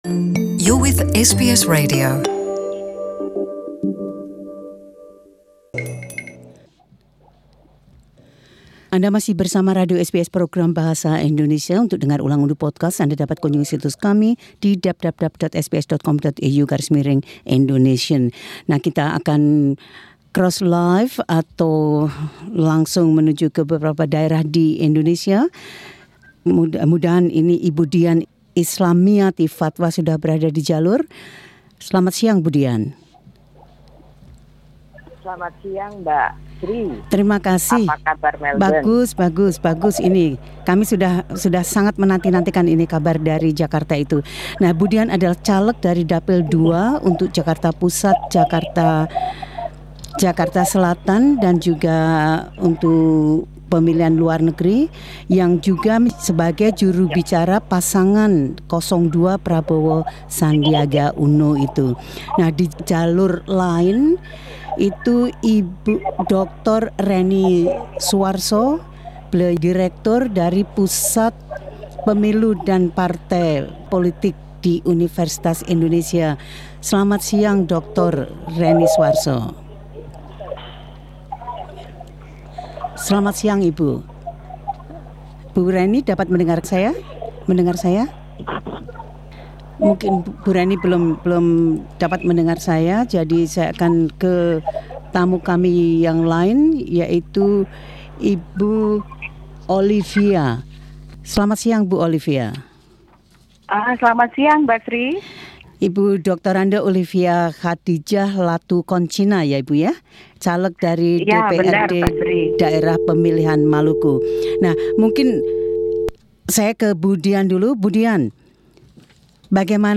Pada hari pemungutan suara, suatu panel yang berasal dari beberapa saerah di Indonesia